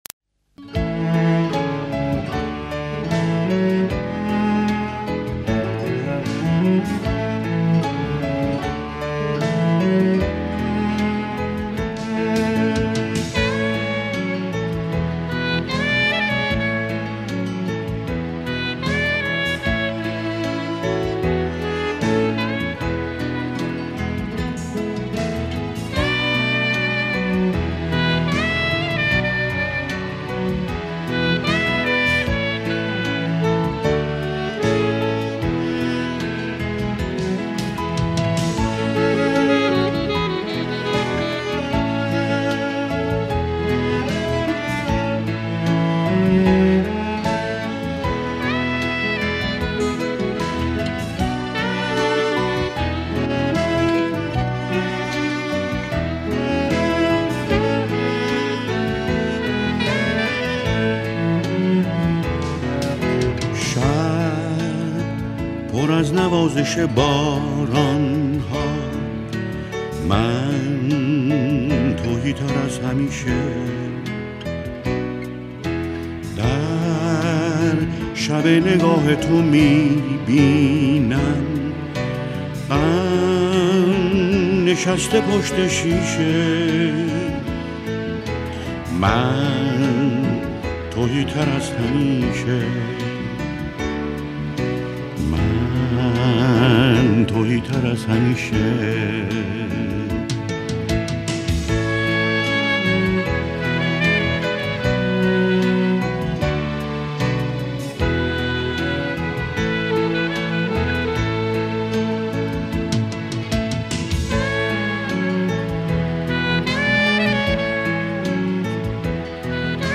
موزیک ایرانی با وایب cozy
Night vibes Cozy vibes